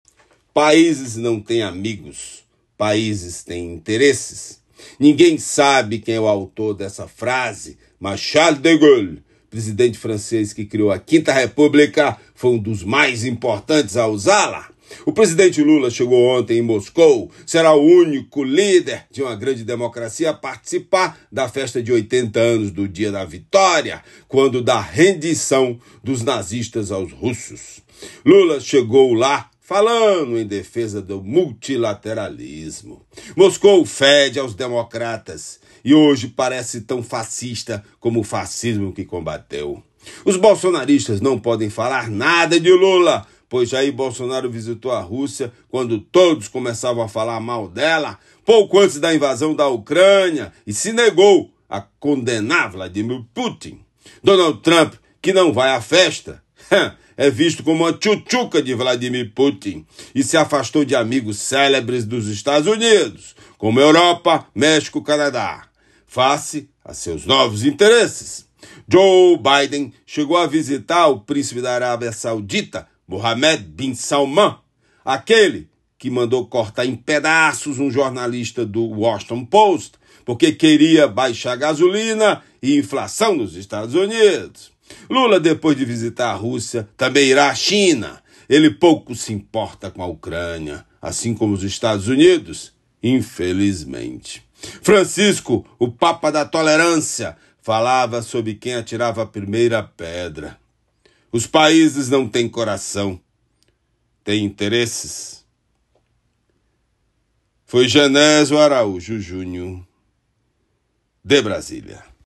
COMENTÁRIO
Comentário do jornalista